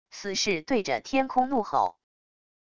死氏对着天空怒吼wav音频